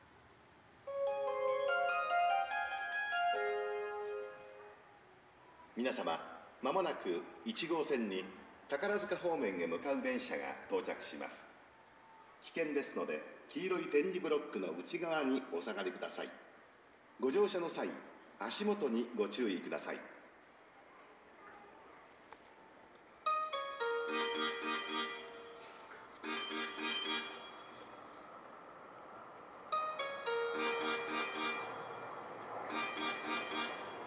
この駅では接近放送が設置されています。
接近放送各駅停車　宝塚行き接近放送です。